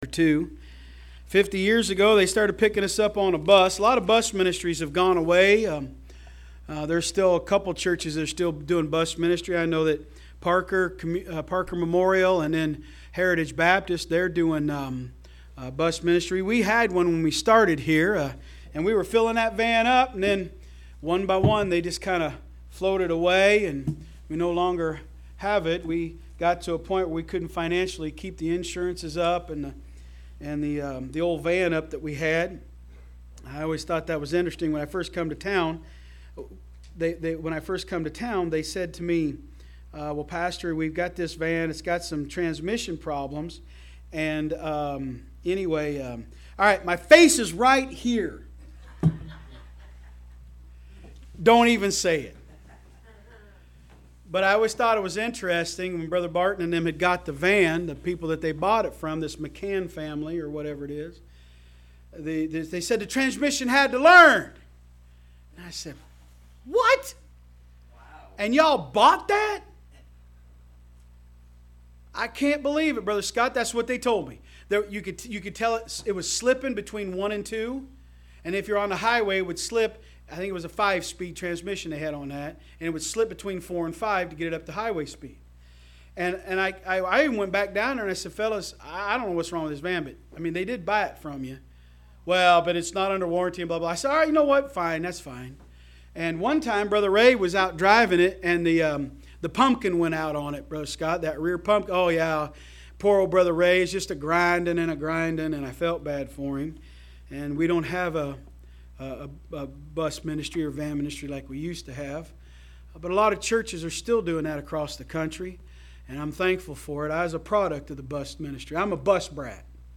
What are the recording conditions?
From Series: "AM Service"